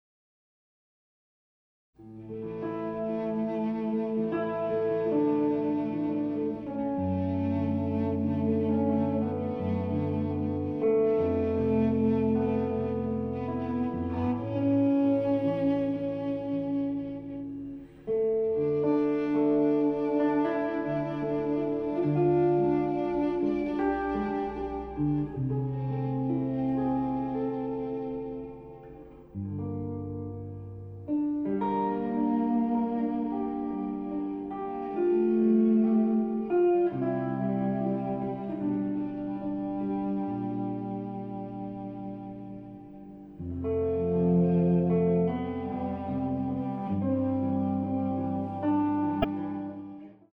爵士大提琴跨界專輯